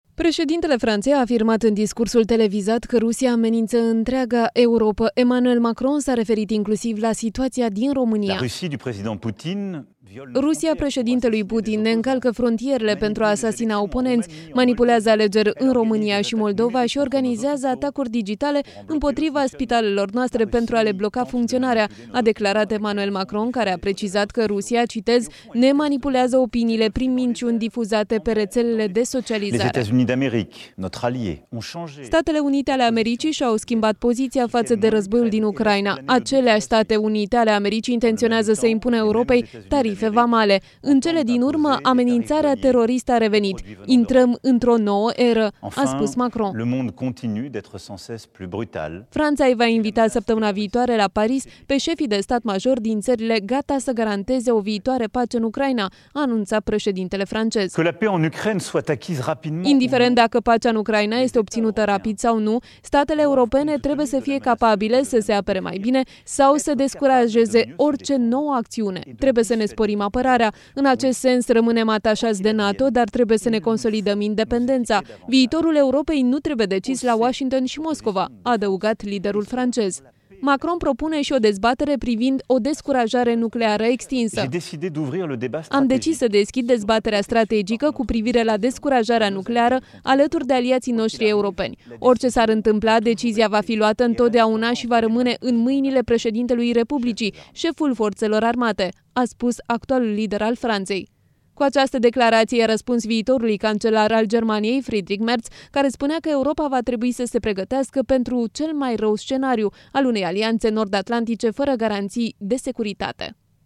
„Viitorul Europei nu trebuie decis la Washington și Moscova” –  a fost unul dintre mesajele transmise în discursul în fața națiunii franceze susținut aseară de președintele Emmanuel Macron.
Preşedintele Franţei a afirmat în discursul televizat că Rusia amenință întreaga Europă.
06mar-07-July-SNDW-Discurs-Macron-despre-amenintarea-Rusiei-alegerile-din-Romania-manipulate.mp3